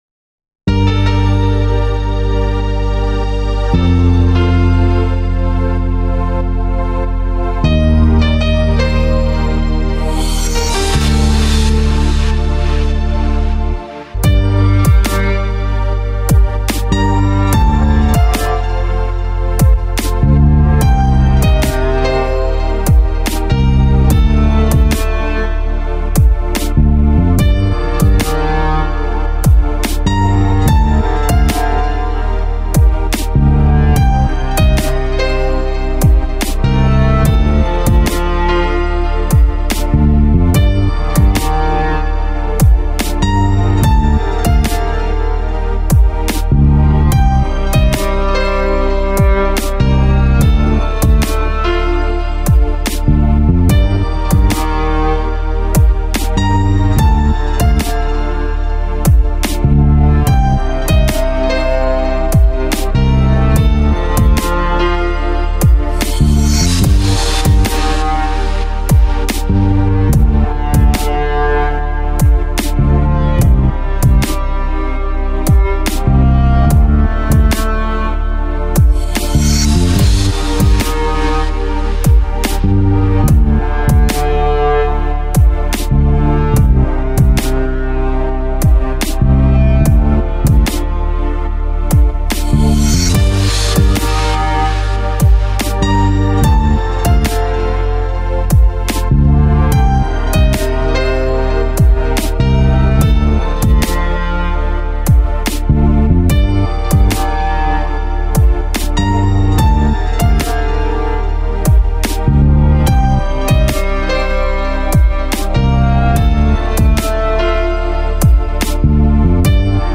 دانلود بیت رپ